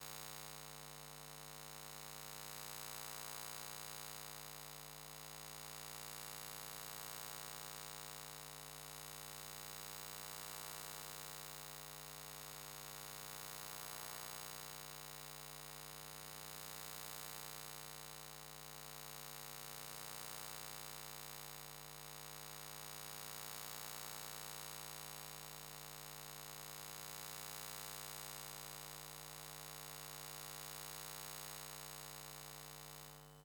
Звуки электромагнитного поля
Звук легкого жужжания электромагнитного поля